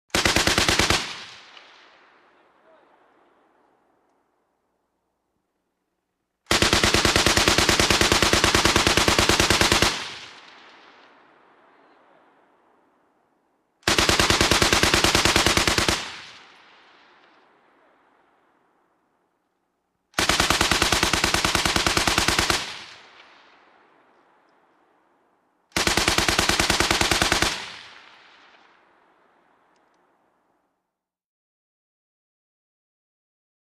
AutoMachGunBurst PE706201
WEAPONS - MACHINE GUNS HEAVY SQUAD AUTOMATIC WEAPON: EXT: Long bursts, with long fading echo.